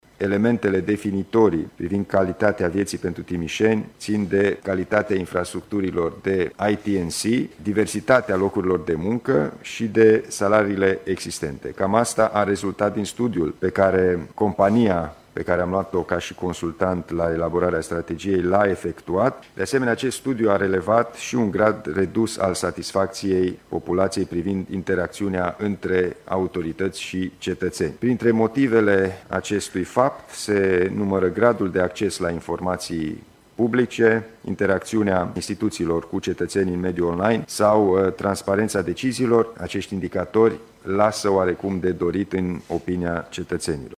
Una dintre vulnerabilități ține chiar de calitatea vieții, spune președintele instituției, Alin Nica.